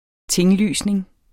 Udtale [ ˈteŋˌlyˀsneŋ ]